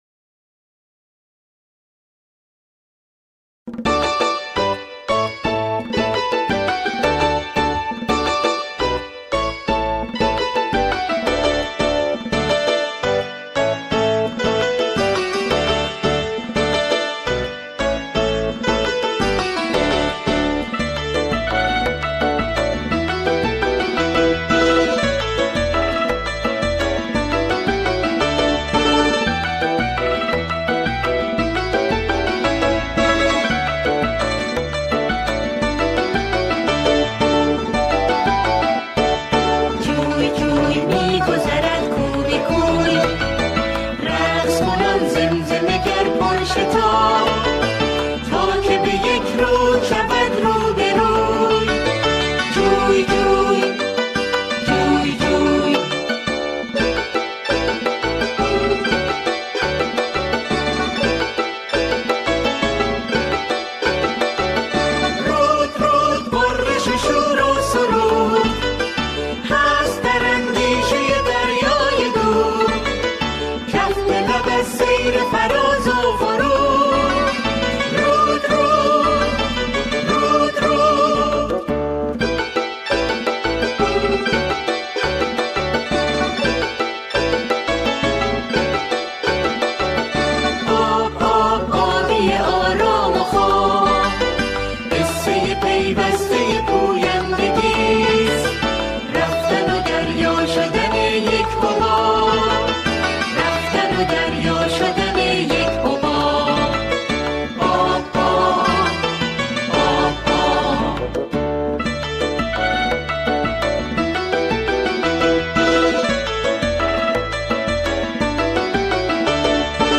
سرود کودکانه